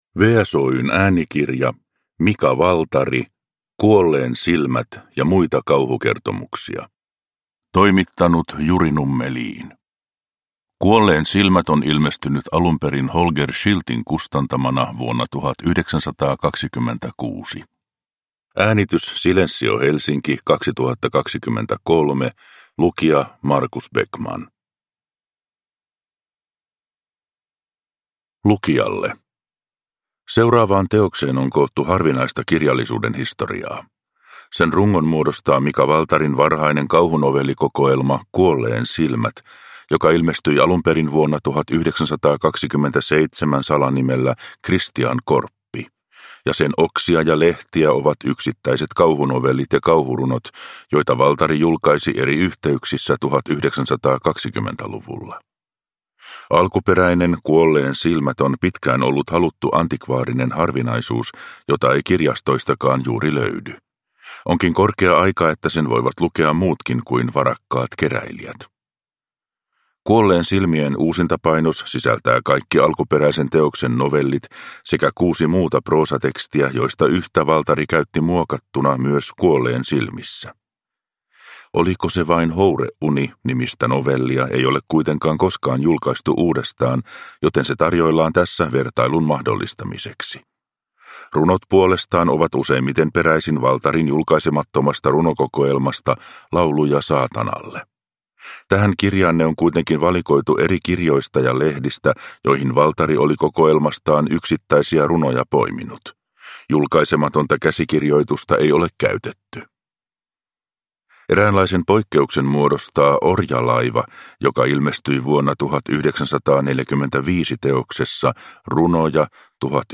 Kuolleen silmät – Ljudbok – Laddas ner